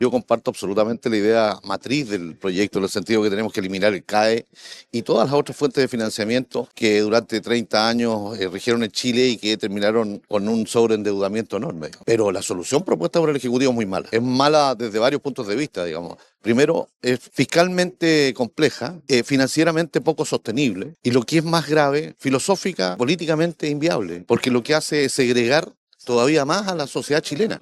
Por su parte, el integrante de la Comisión de Hacienda de la Cámara, diputado Ricardo Cifuentes, señaló que propuesta del Ejecutivo “es mala”.